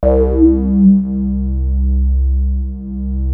JUP 8 C3 6.wav